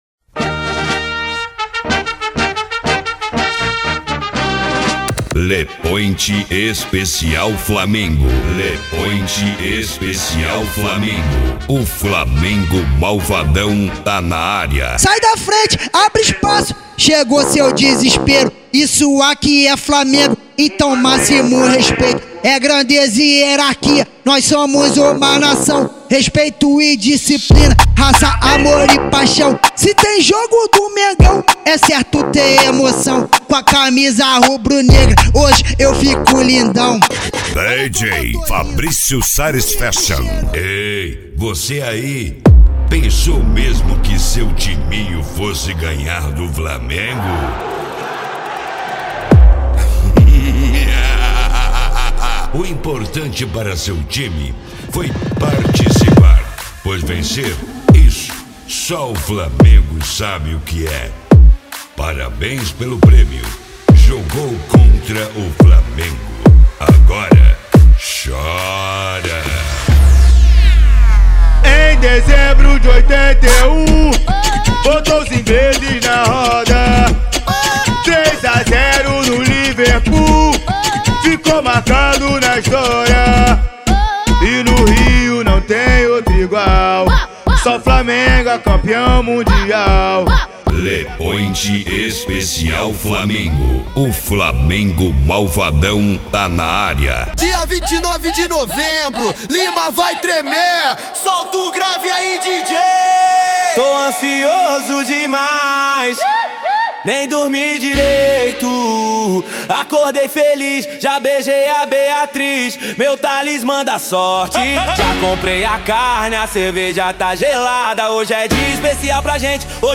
Funk
Mega Funk